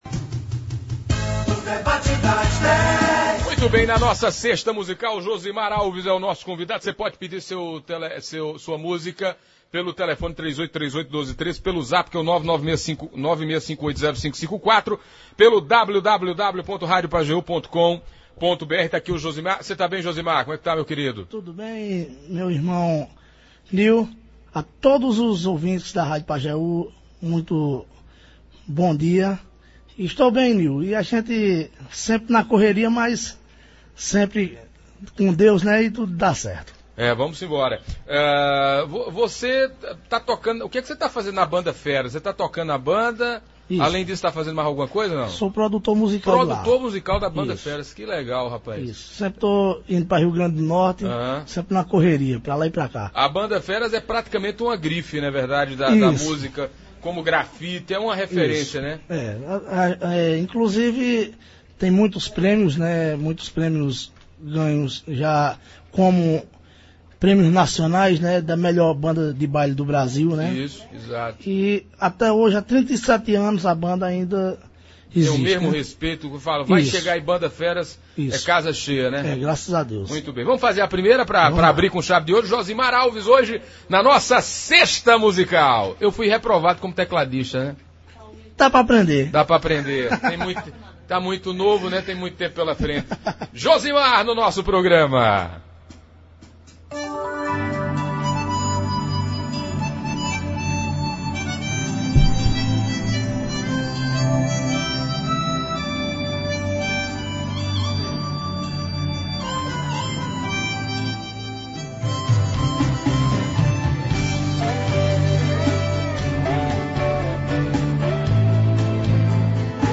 teclado
fez a festa nos estúdios da Pajeú. Com repertório eclético e de bom gosto, caiu nas graças dos ouvintes e internautas que foram só elogios ao músico.